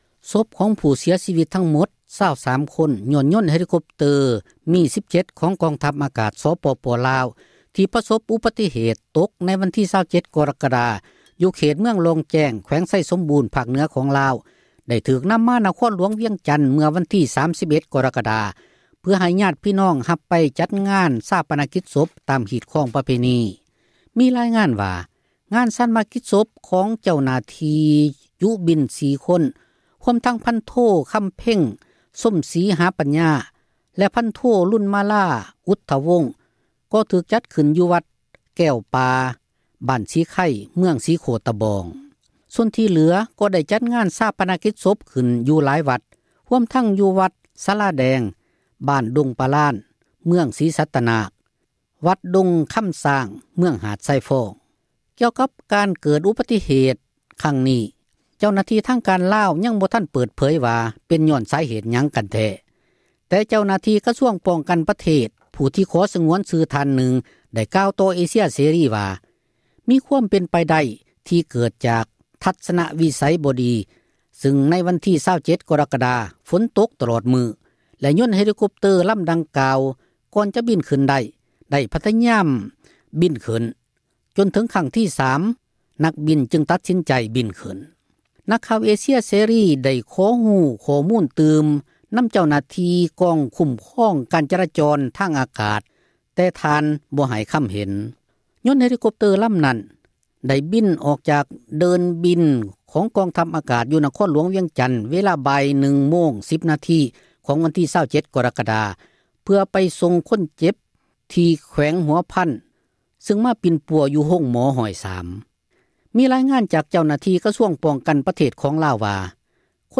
ພິທີສົ່ງສະການຜູ້ເຄາະຮ້າຍ ຍົນຕົກ – ຂ່າວລາວ ວິທຍຸເອເຊັຽເສຣີ ພາສາລາວ